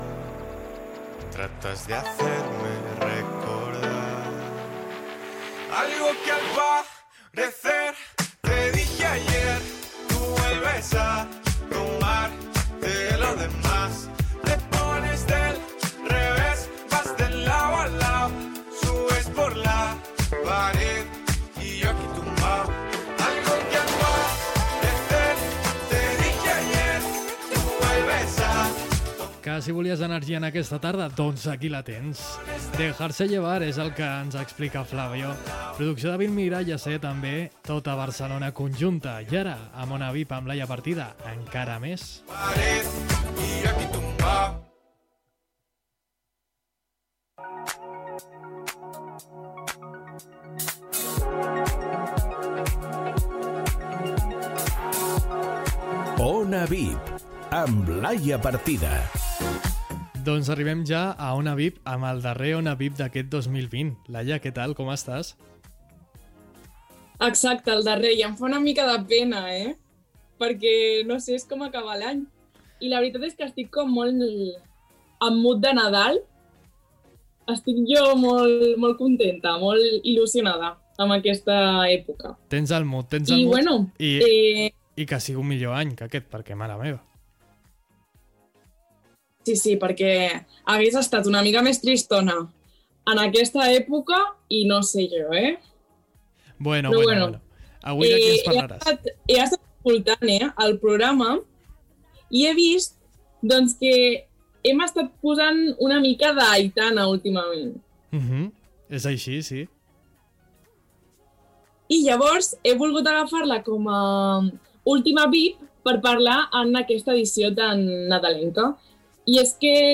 Careta del programa, presentació, sumari, tema musical, indicatiu del programa, entrevista